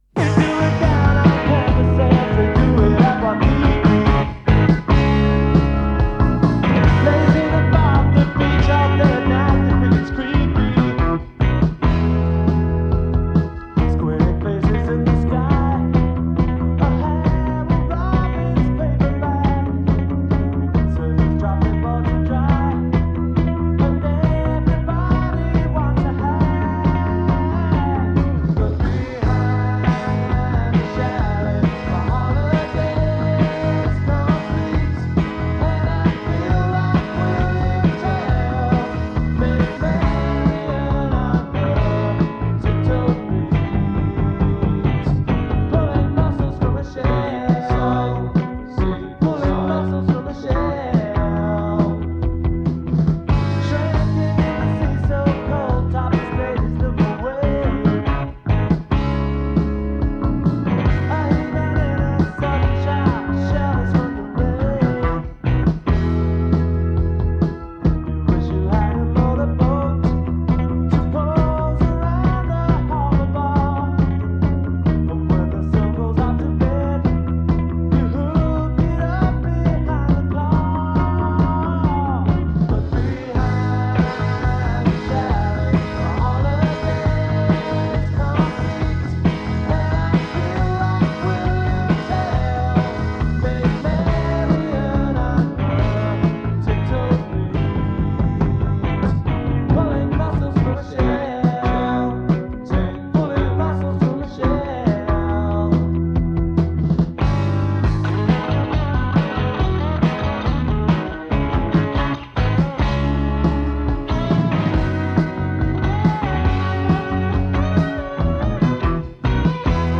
Am I the only one who recorded it off the radio?
I love the bass and the live feel to the recordings.